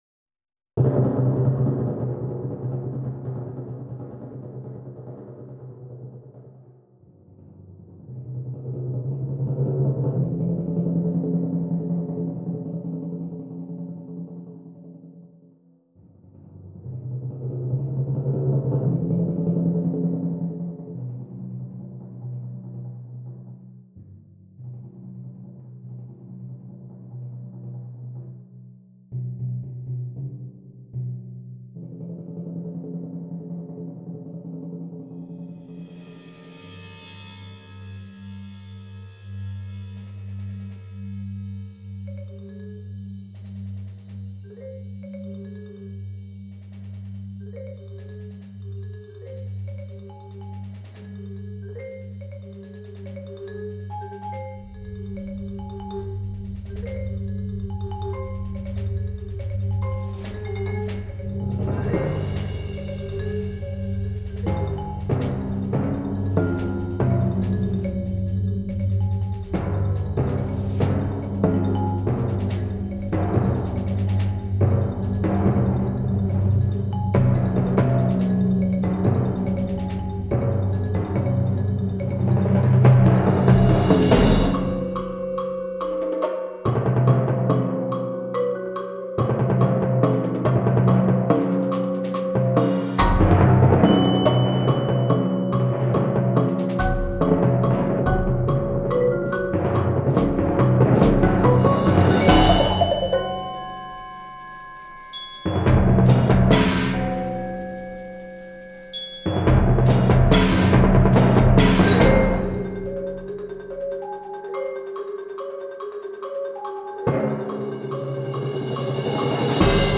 concerto for timpani